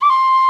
D3FLUTE83#06.wav